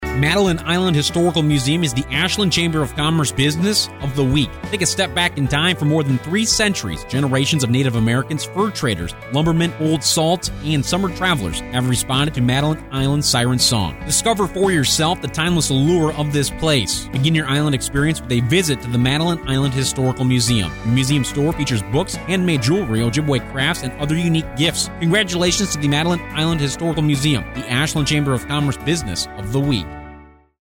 Ashland Chamber’s Business of the Week for November 5, 2018: Madeline Island Historical Museum Each week the Ashland Area Chamber of Commerce highlights a business on Heartland Communications radio station WATW 1400AM. The Chamber draws a name at random from our membership and the radio station writes a 30-second ad exclusively for that business.